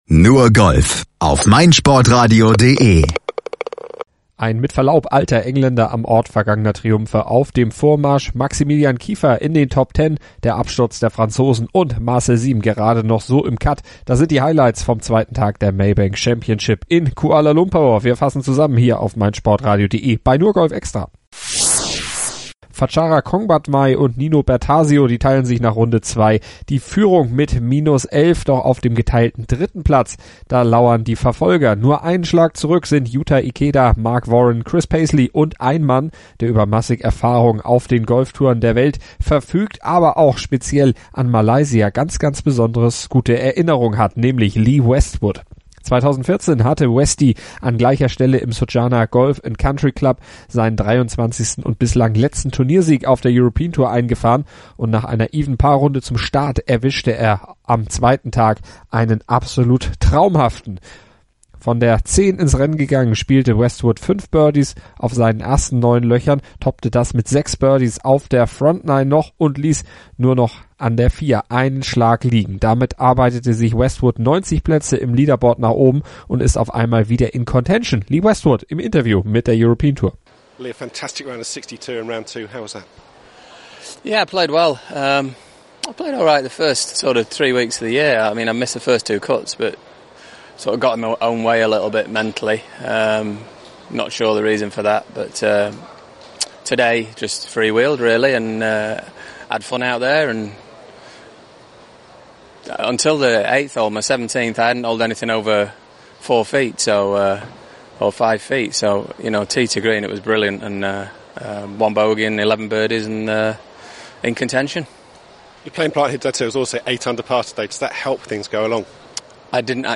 Westwood nach seiner 62 im Interview.